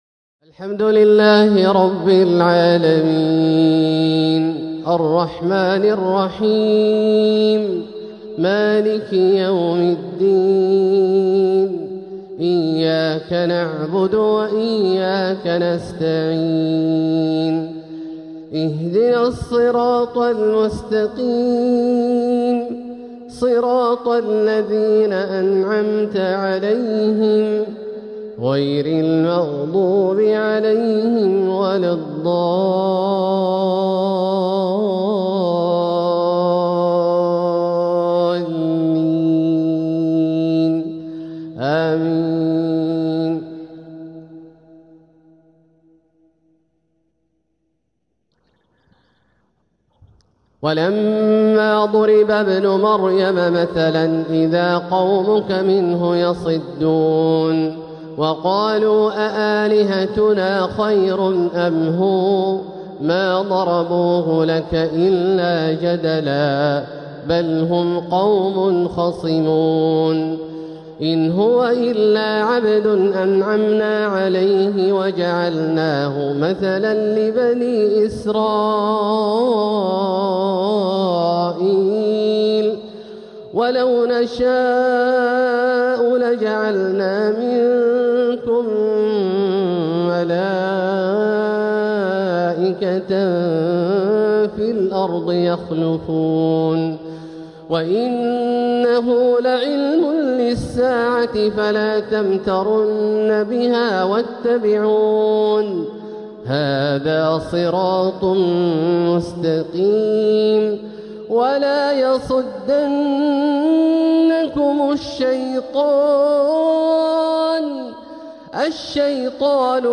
القطف الجني لتلاوات الشيخ عبدالله الجهني | شهر رجب 1447هـ " الحلقة السابعة والثمانون" > القطف الجني لتلاوات الجهني > المزيد - تلاوات عبدالله الجهني